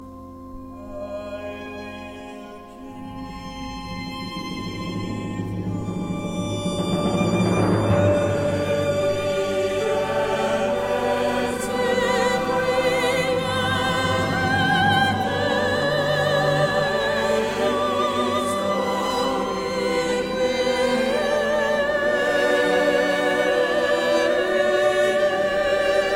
C'est le premier CD avec utilisation de l'orgue de choeur.